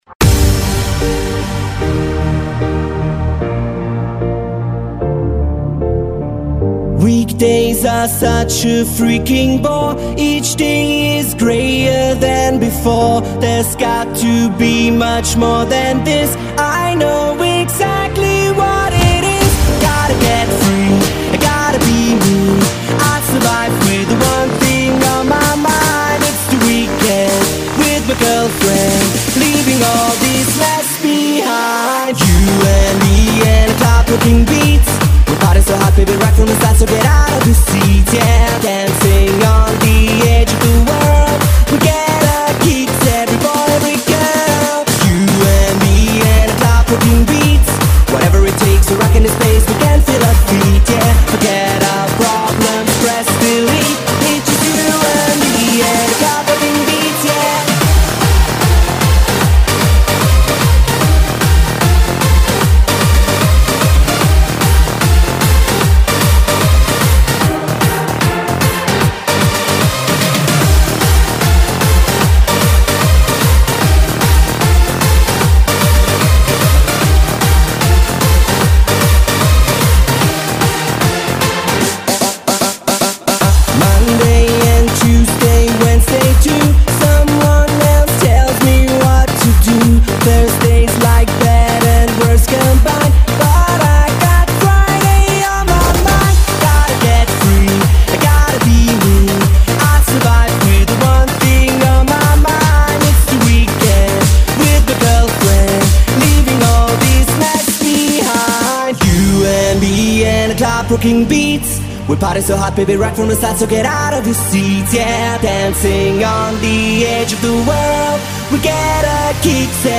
música electrónica dance